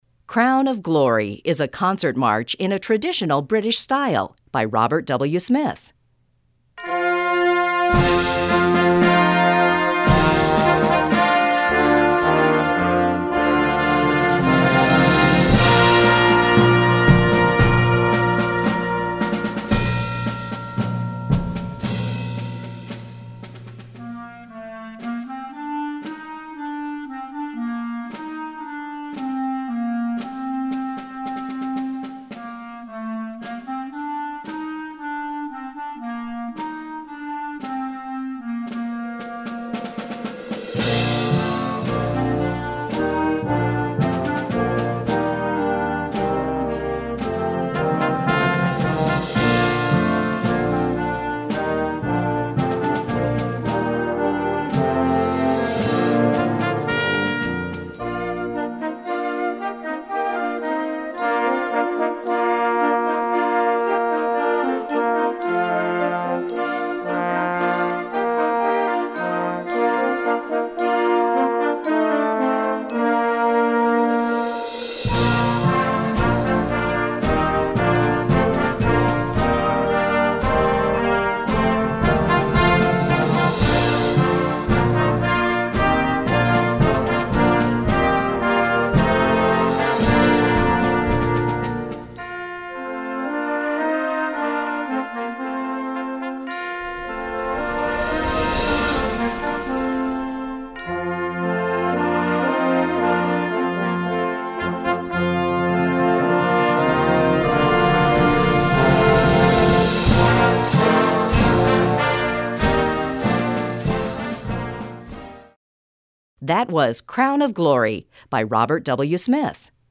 Gattung: für Jugendblasorchester
Besetzung: Blasorchester
a concert march in a traditional British style